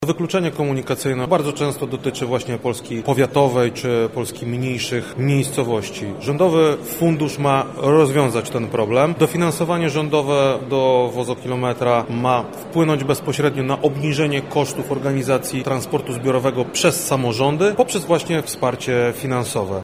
Krzysztof Komorski – mówi Wojewoda Lubelski, Krzysztof Komorski.